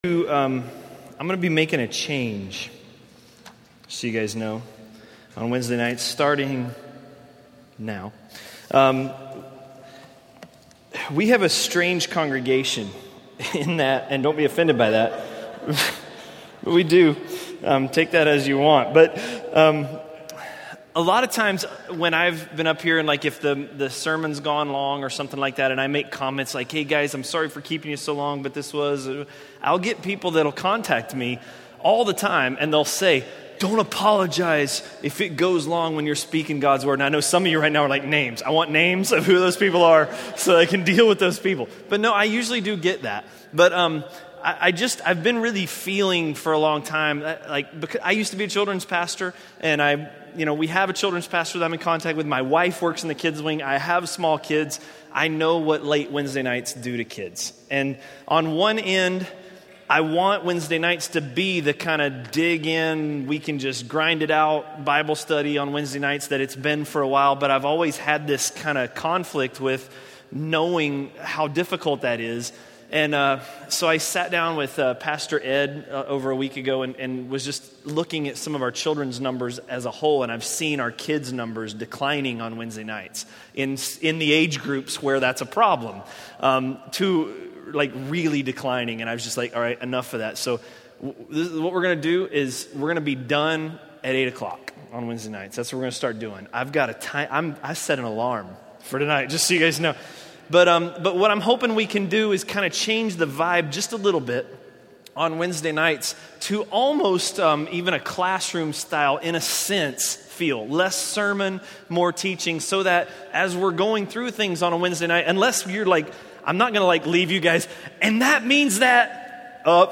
A message from the series "(Untitled Series)." by